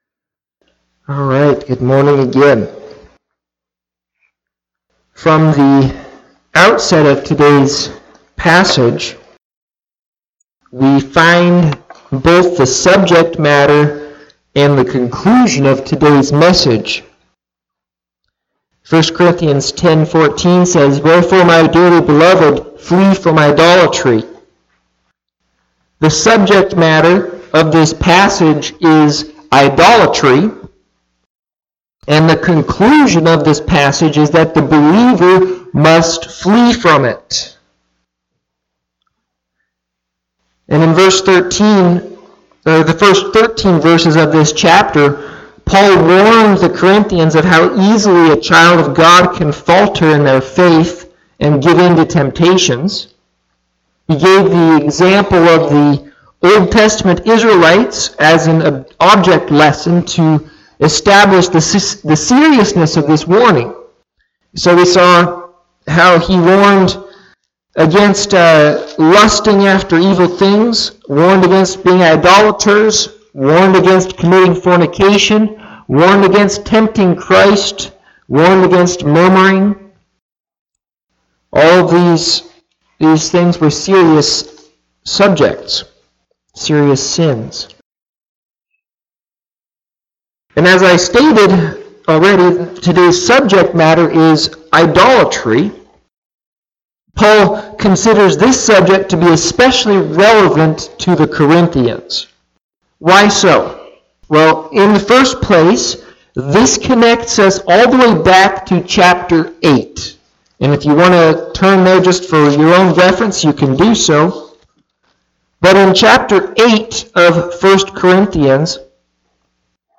Listen to Audio of the sermon or Click Facebook live link above.
Service Type: Morning Sevice